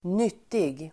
Uttal: [²n'yt:ig]